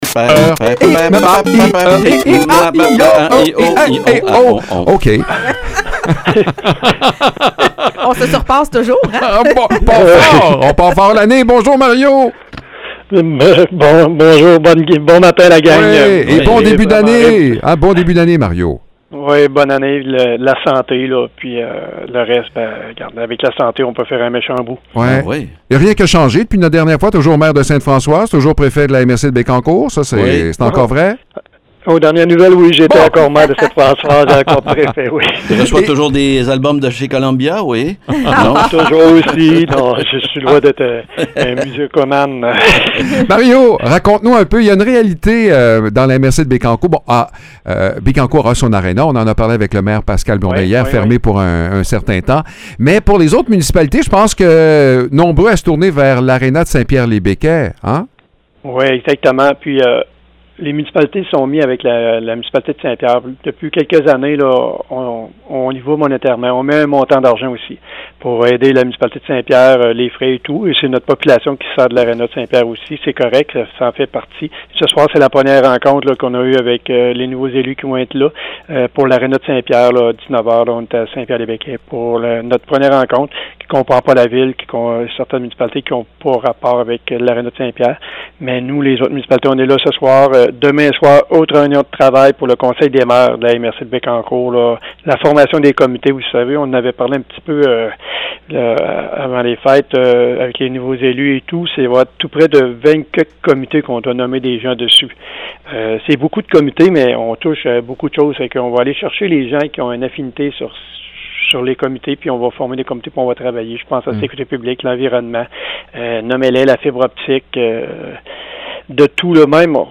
Mario Lyonnais, maire de Sainte-Françoise et préfet de la MRC de Bécancour, annonce que plusieurs comités seront officiellement nommés demain soir. Il profite également de l’occasion pour sensibiliser la population à l’importance d’économiser l’eau potable.